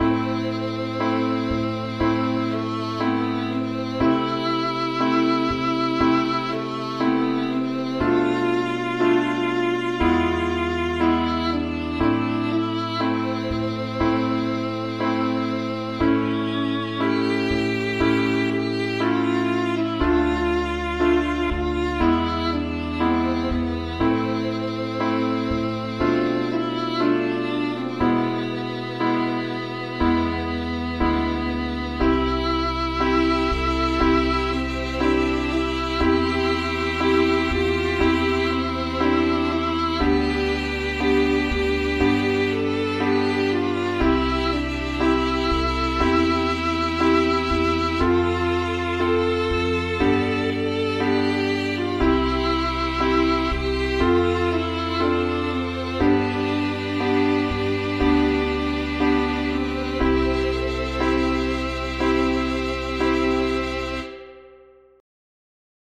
озвучены программой timidity.
дополнительно живая игра на синтезаторе.